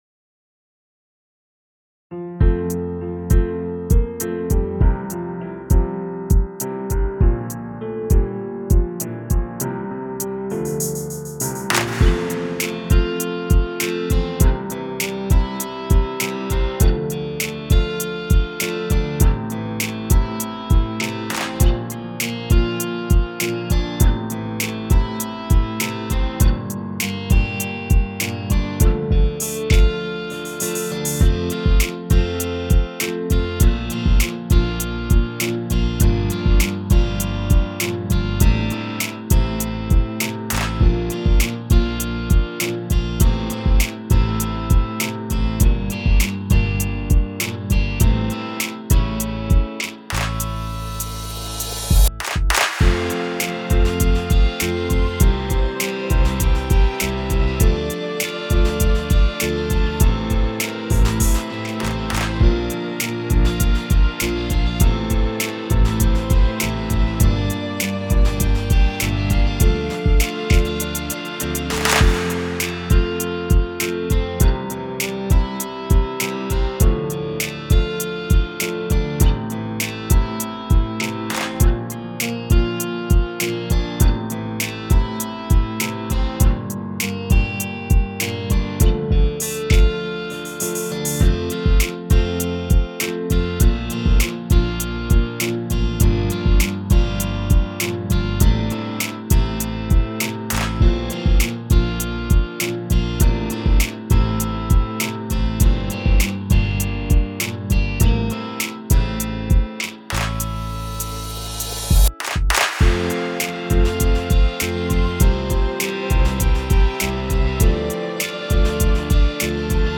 インスト音源 / コード譜 配布
目指していたイメージとは少し違った着地点にはなりましたが、おかげで透き通るようなクリーンな楽曲にはなりました。